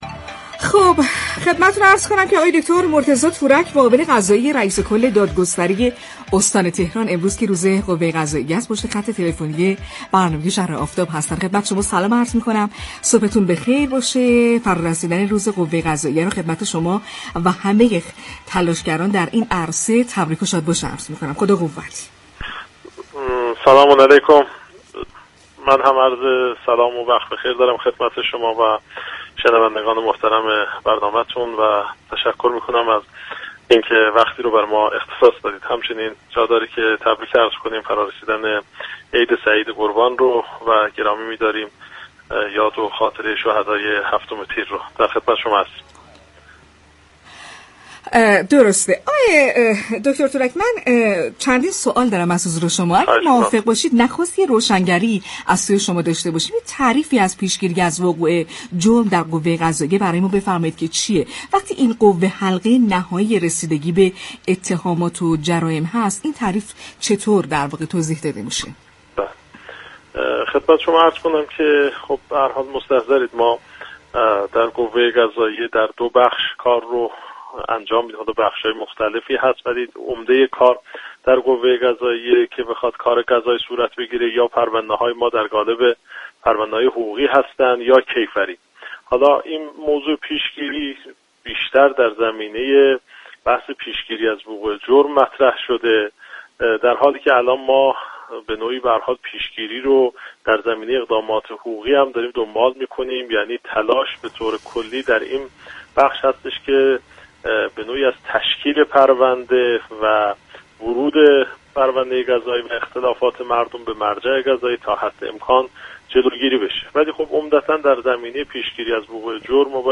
به گزارش پایگاه اطلاع رسانی رادیو تهران، مرتضی تورك معاون قضایی رئیس كل دادگستری استان تهران همزمان با 7 تیر ماه روز قوه قضاییه در گفت و گو با «شهر آفتاب» اظهار داشت: اگر هر سازمان و نهادی به وظایف خود در پیشگیری از وقوع جرم عمل كند؛ از تشكیل پرونده در مراجع قانونی جلوگیری می‌شود.